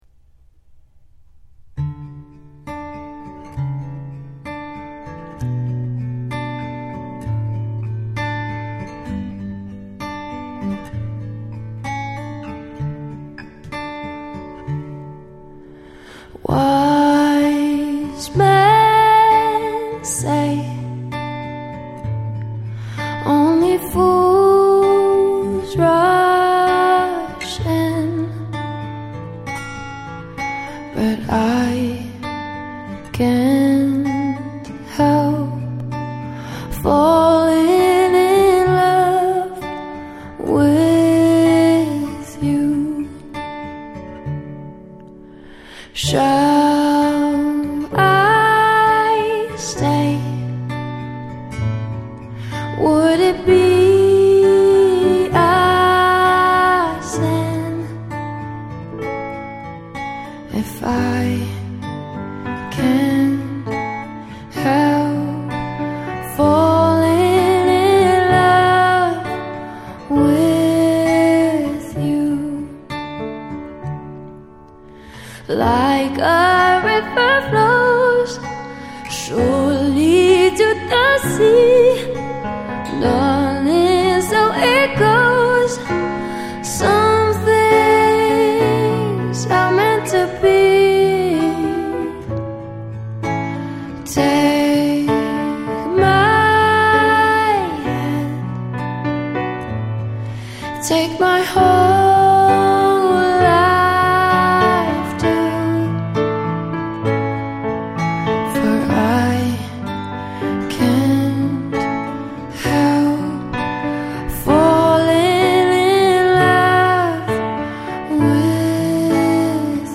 a classically trained pianist and a formidable vocalist